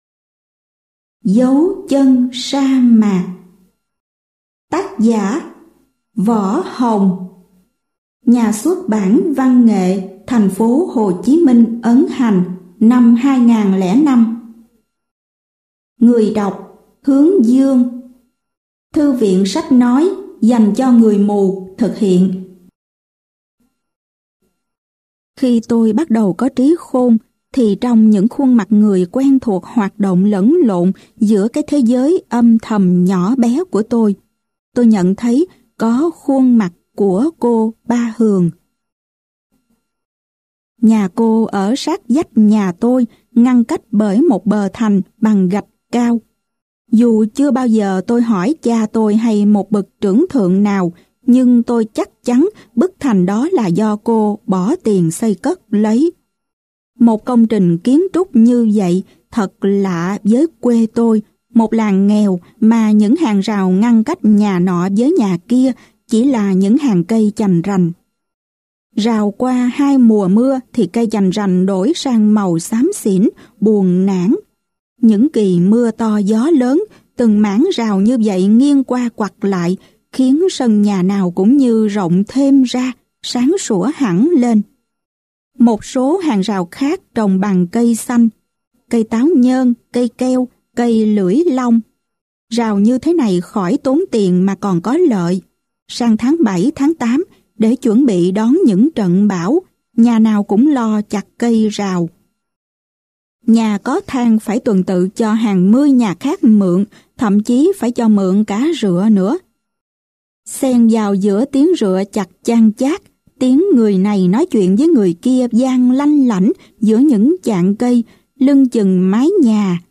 Sách nói Dấu Chân Sa Mạc - Võ Hồng - Sách Nói Online Hay